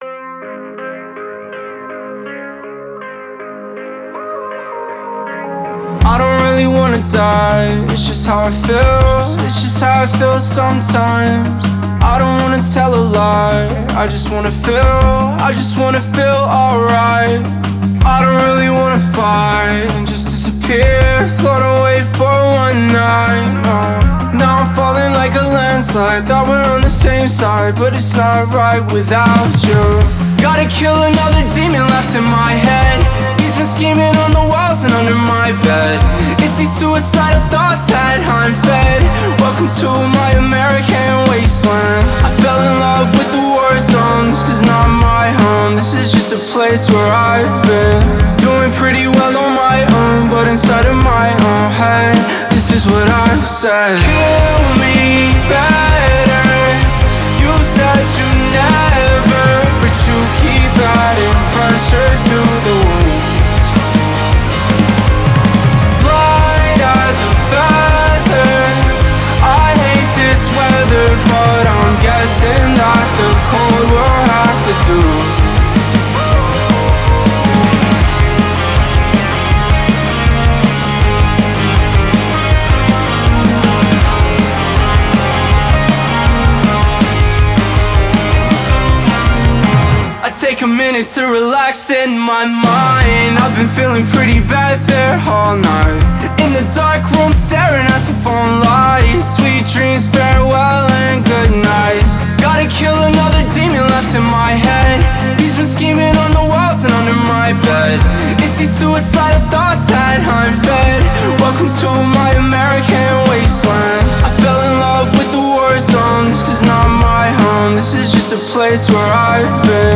это динамичная электронная композиция в жанре EDM